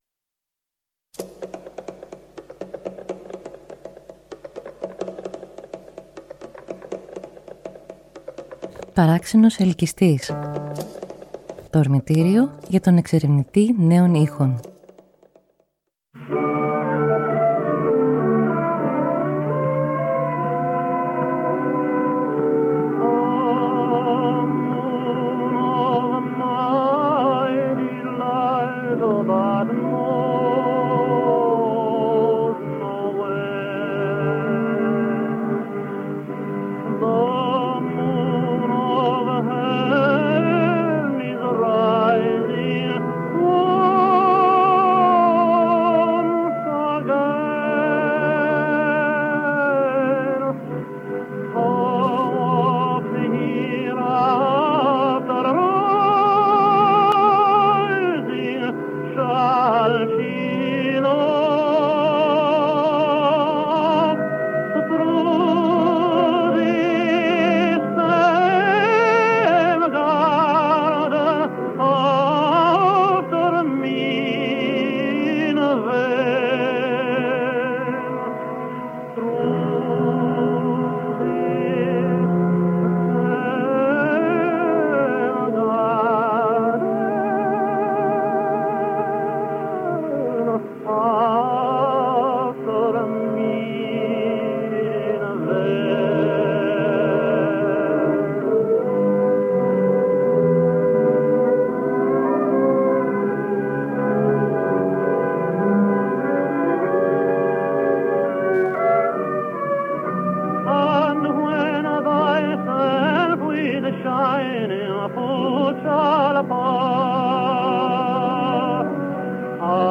[Μεγαλοπρεπής_θόρυβος_φτιαγμένος_στο_όμορφο_σάπιο_Ιράν] μέρος_Α: Σε έναν Περσικό κήπο, ερειπωμένο.